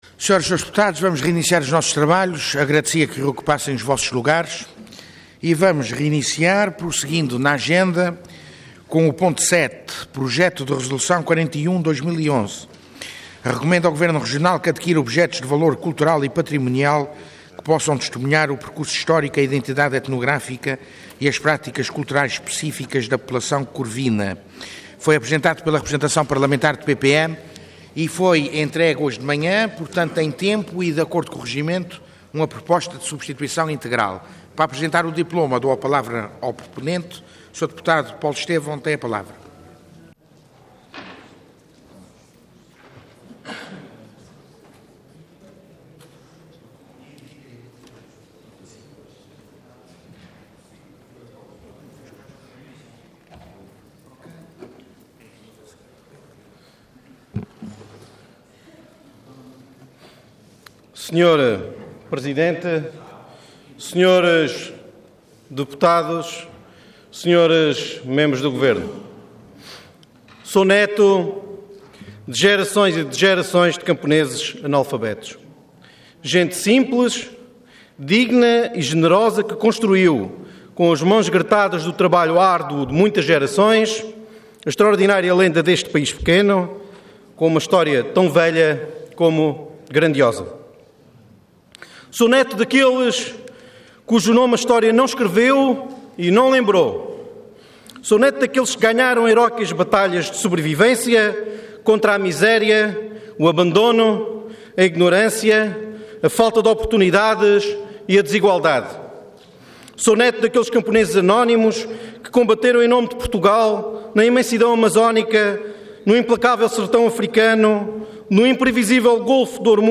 Intervenção Projeto de Resolução Orador Paulo Estêvão Cargo Deputado Entidade PPM